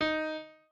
piano3_3.ogg